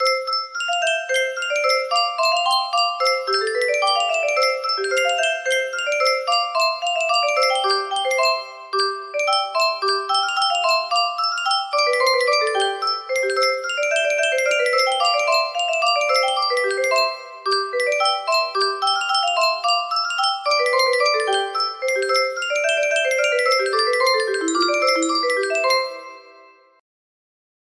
Ode to Joy - Beethoven music box melody